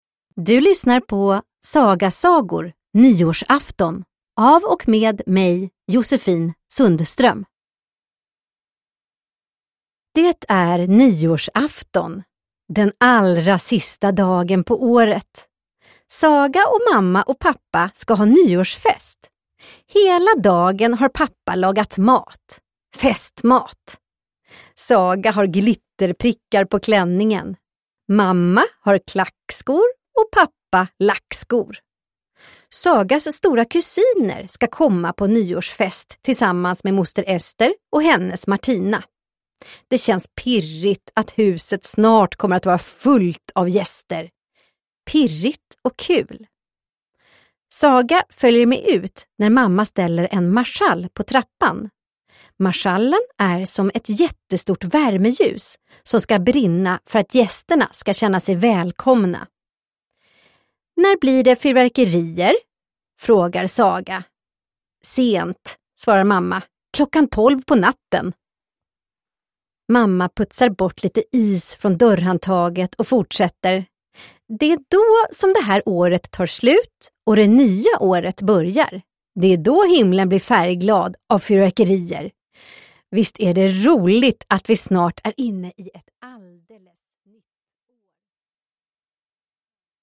Nyårsafton – Ljudbok – Laddas ner